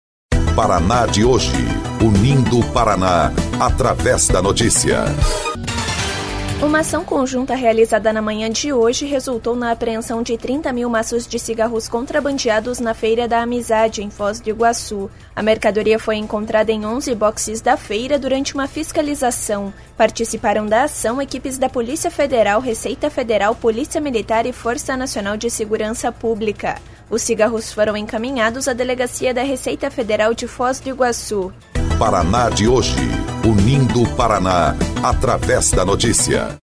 BOLETIM – Ação conjunta apreende 30 mil maços de cigarro na Feira da Amizade